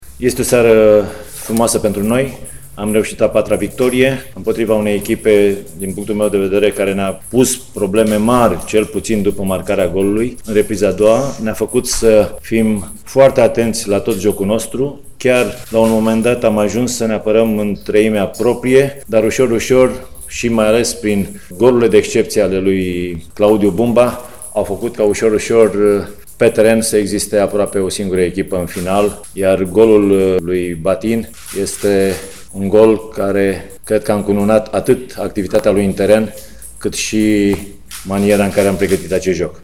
Tehnicianul ilfovenilor, Ion Moldovan, a declarat că Poli a pus probleme mari  după încasarea primului gol.